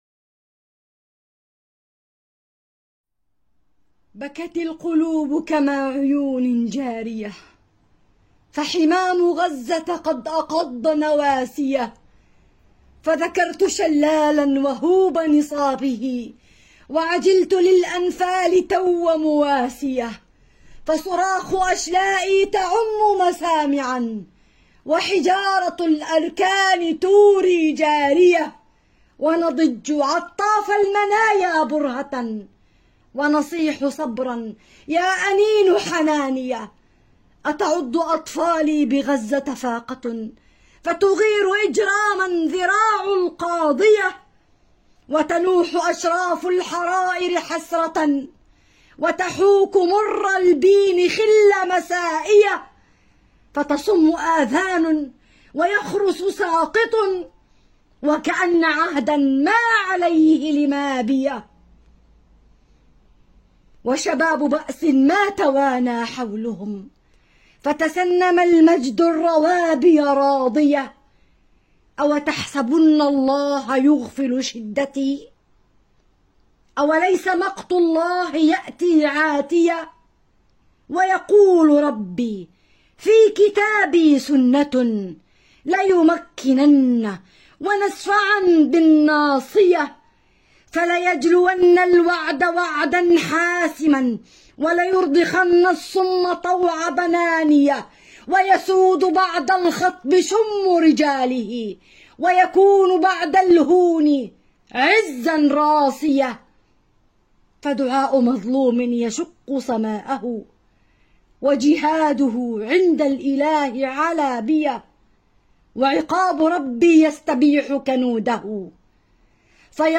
أبيات شعرية - عن مأساة المسلمين في غزة بقلم الأخت